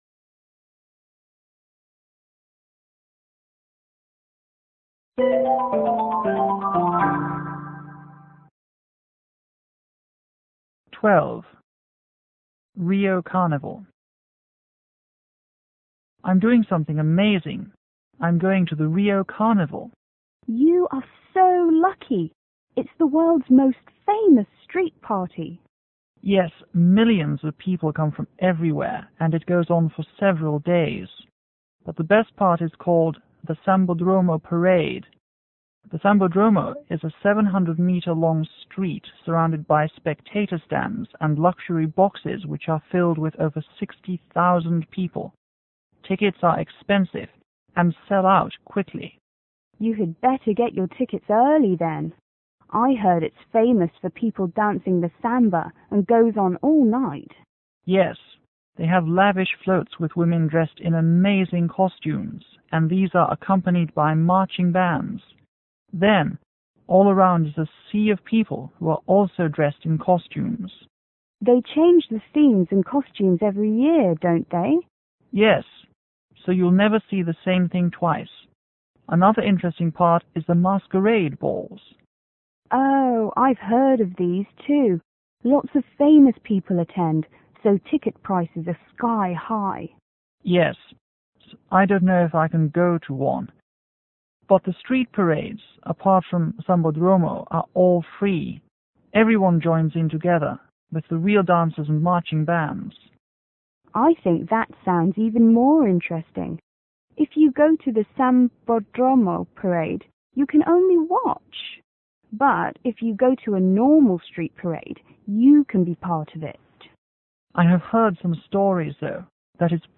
S1: Student 1      S2 : Student 2